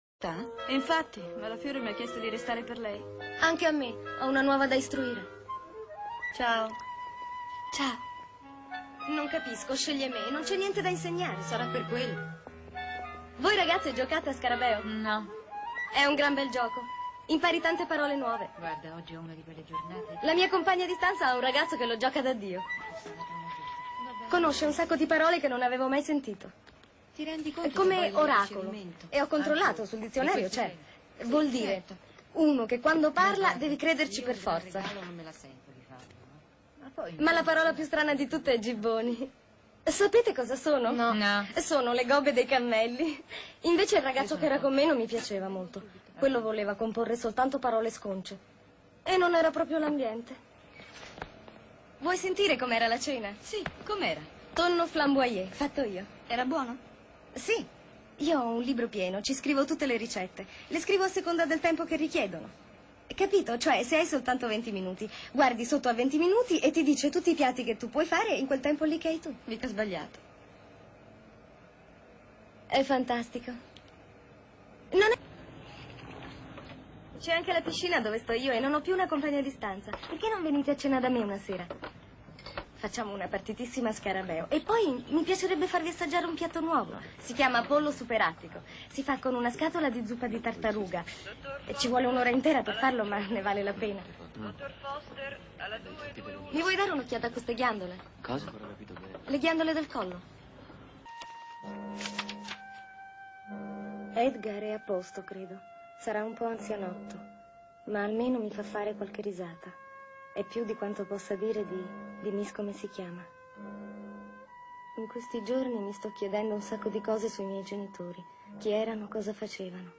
nel film "Tre donne", in cui doppia Shelley Duvall.